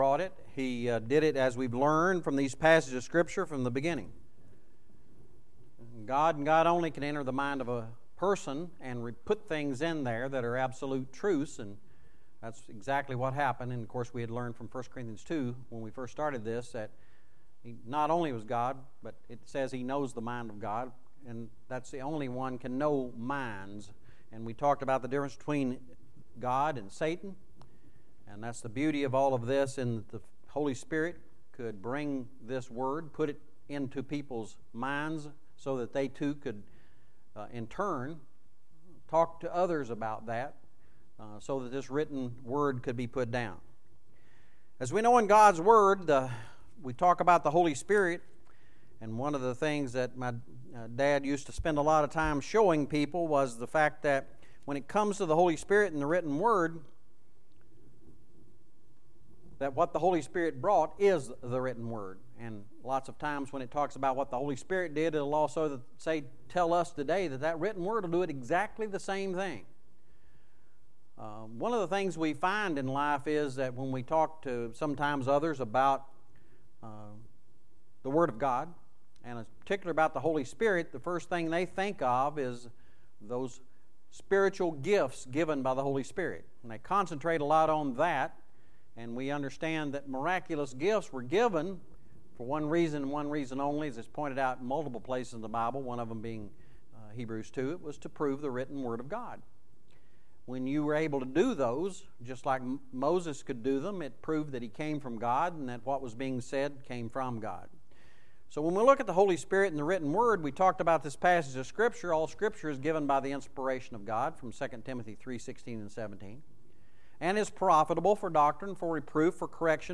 Another lesson in a series of requested lessons is on Holy Spirit – Revelation.